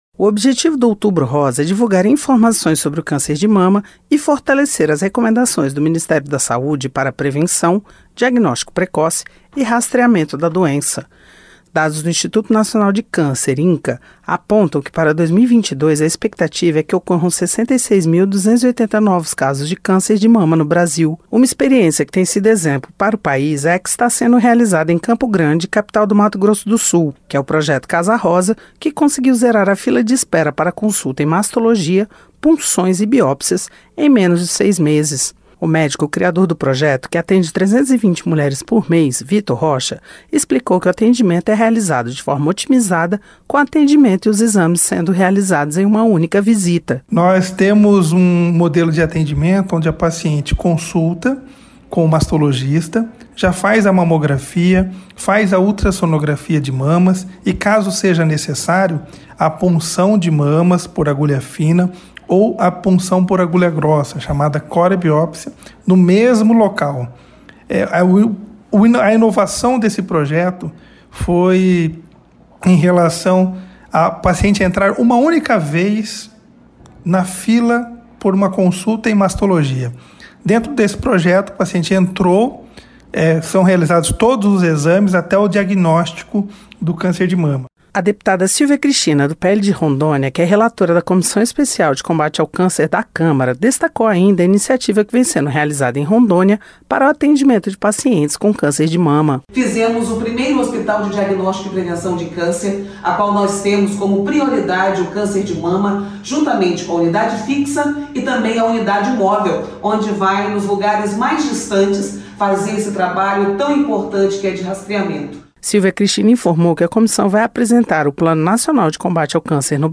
EM MEIO AO OUTUBRO ROSA DEPUTADAS OUVEM QUE ACESSO A EXAMES TEM SIDO BARREIRA NO TRATAMENTO DO CÂNCER DE MAMA NO BRASIL. A REPÓRTER